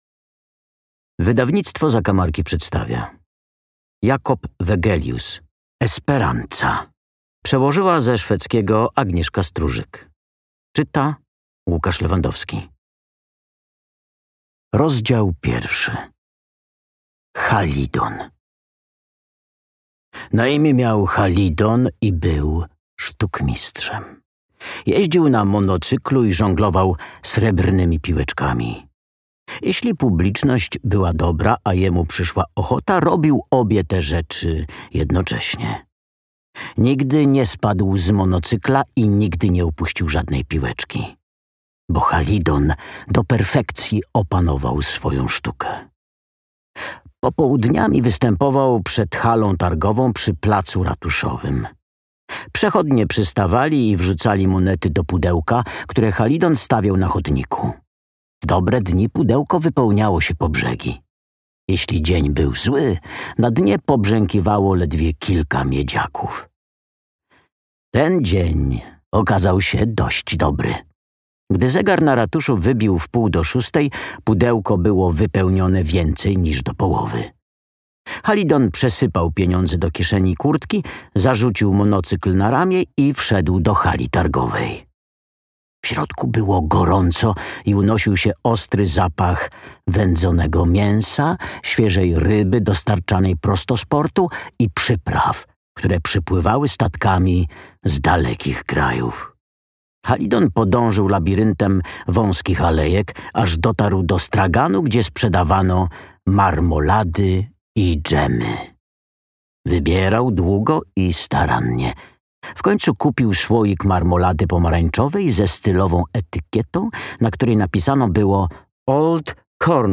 Esperanza - Jakob Wegelius - audiobook + książka